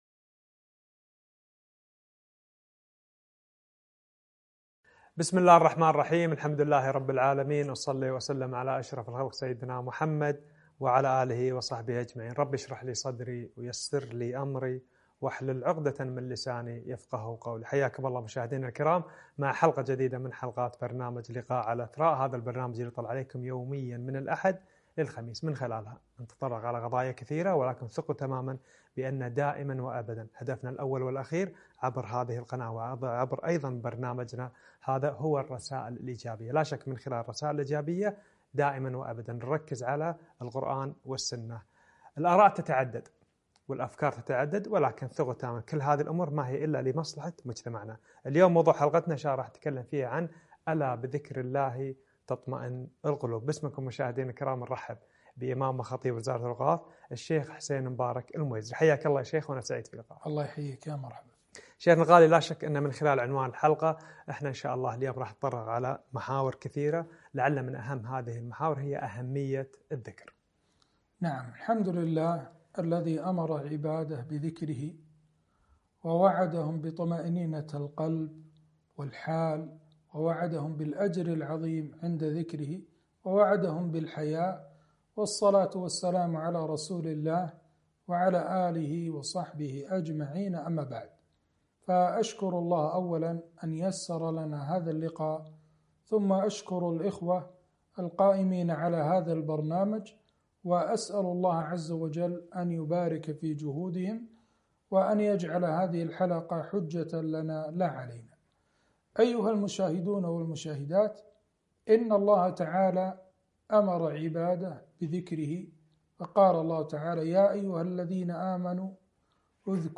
ألا بذكر الله تطمئن القلوب - لقاء على قناة إثراء